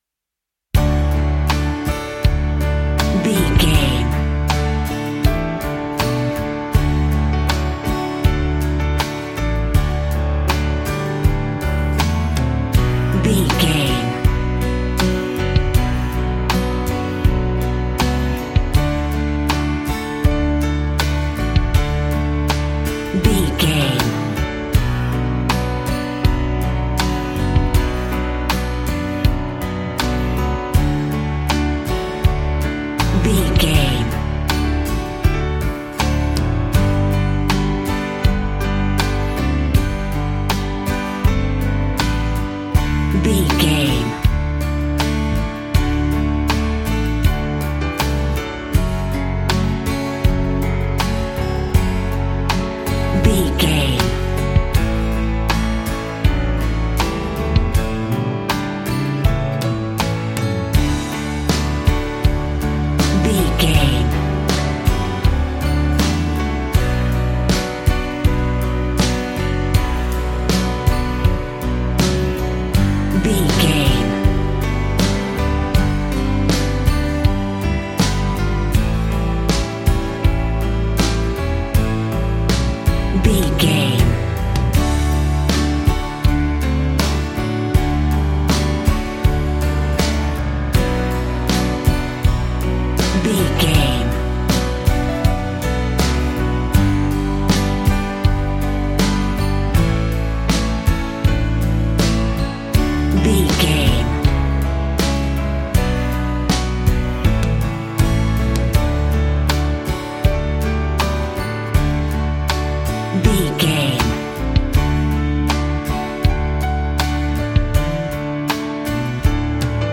Ionian/Major
romantic
sweet
acoustic guitar
bass guitar
drums